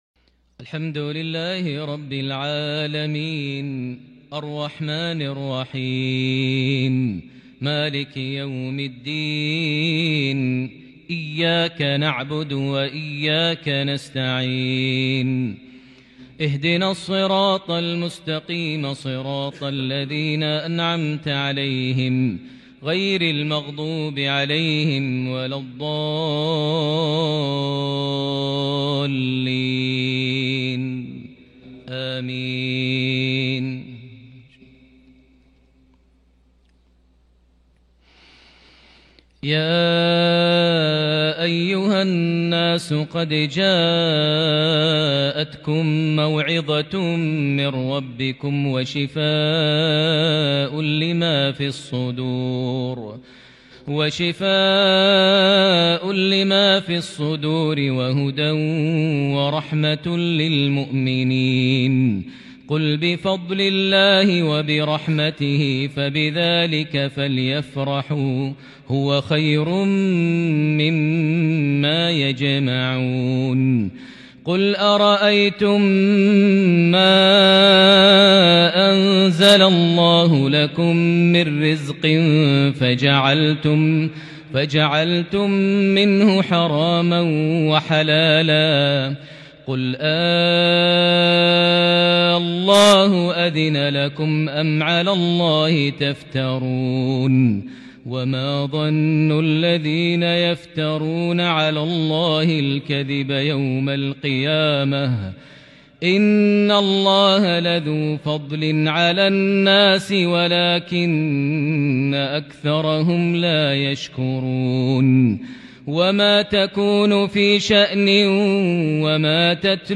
صلاة المغرب من سورة يونس 23 جمادى الآخر 1442هـ | mghrip 5-2-2021 prayer fromSurah Yunus > 1442 🕋 > الفروض - تلاوات الحرمين